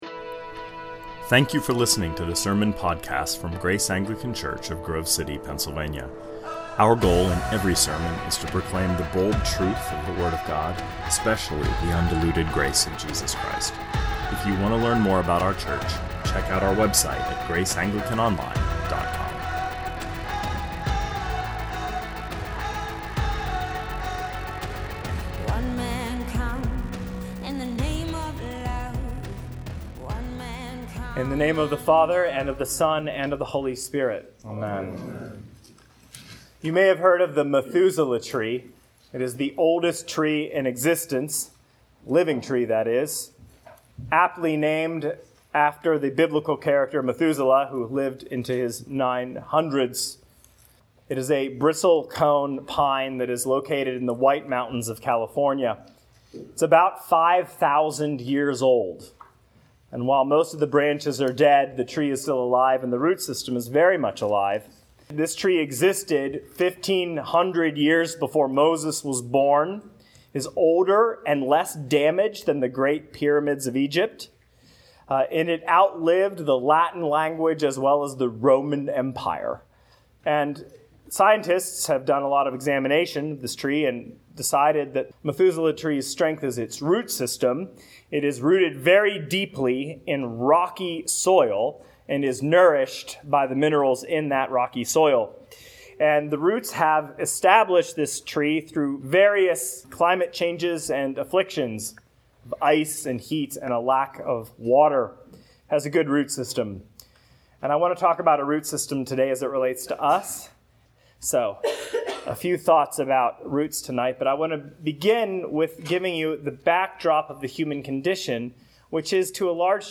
A homily on Grace’s Mission Statement and the first of its core values (Roots).